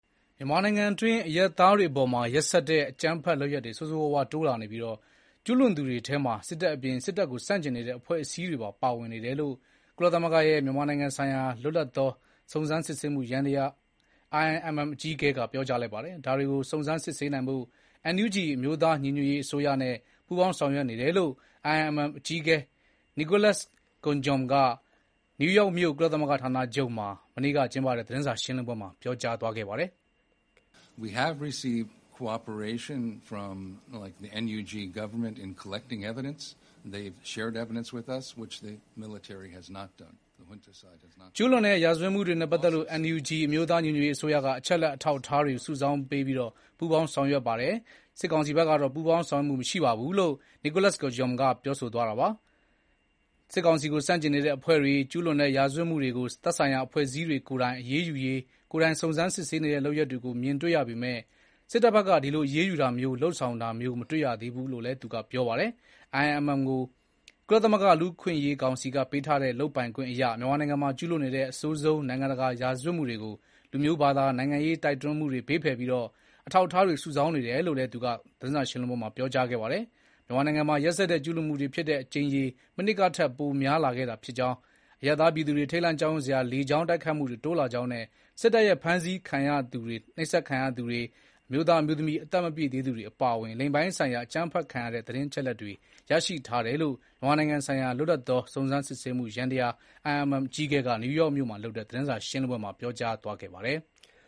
ဒါတွေကို စုံစမ်းစစ်ဆေးနိုင်ဖို့ NUG အမျိုးသား ညီညွတ်ရေးအစိုးရနဲ့ ပူးပေါင်းဆောင်ရွက်နေတယ်လို့ IIMM အကြီးအကဲ Nicholas Koumjian က နယူးယောက်မြို့ ကုသလဂ္ဂ ဌာနချုပ်မှာ မနေ့က ကျင်းပတဲ့ သတင်းစာရှင်းလင်းပွဲမှာ ပြောကြားသွားပါတယ်။